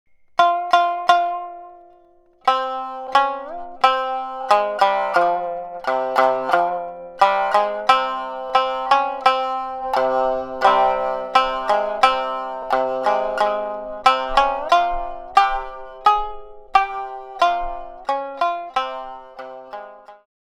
Traditional fishing folk song (Minyo) for shamisen.
• niagari tuning (C-G-C)